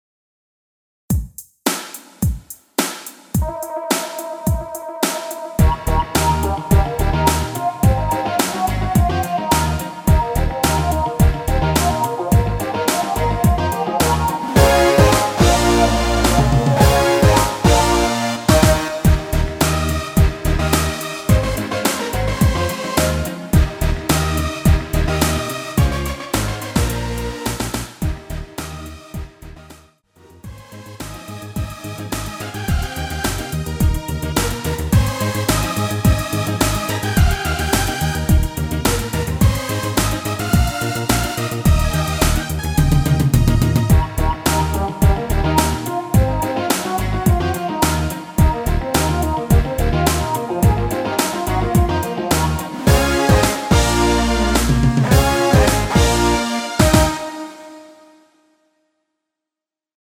Eb
멜로디 MR이라고 합니다.
앞부분30초, 뒷부분30초씩 편집해서 올려 드리고 있습니다.
중간에 음이 끈어지고 다시 나오는 이유는